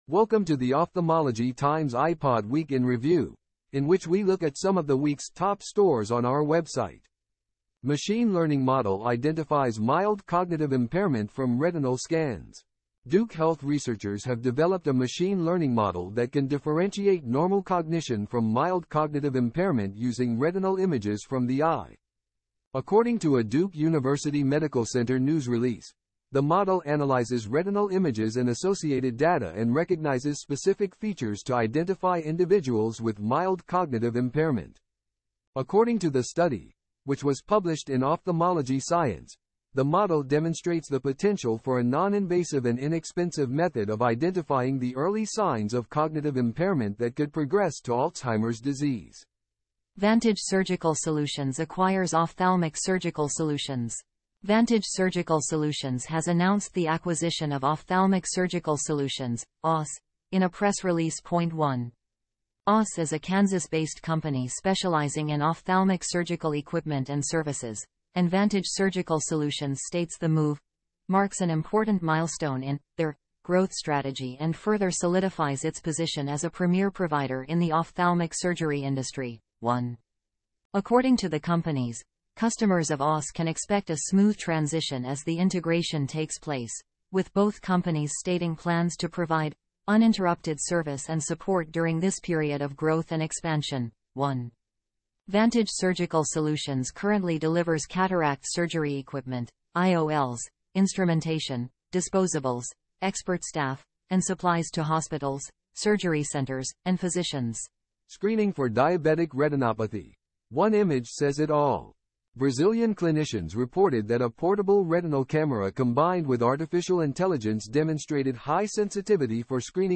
Editor's Note: This podcast was generated from Ophthalmology Times content using an AI platform.